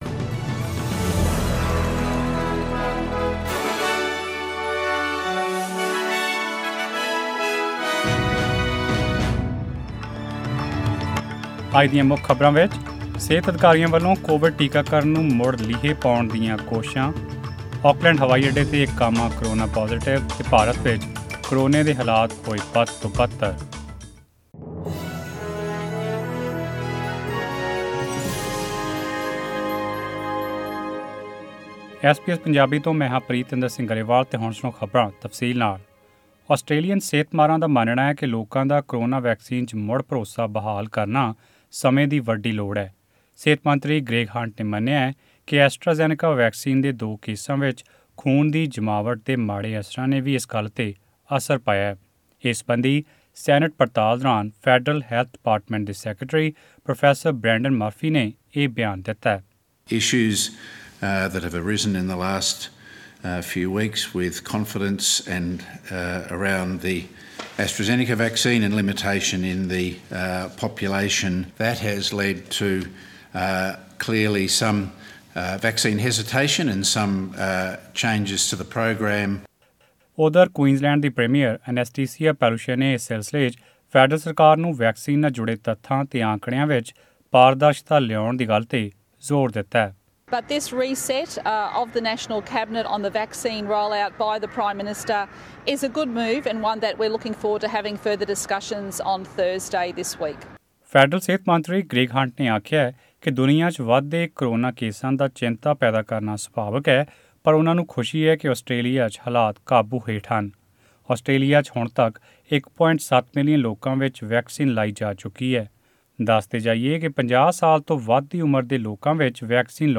This news and more in tonight’s news bulletin….